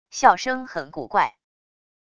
笑声很古怪wav音频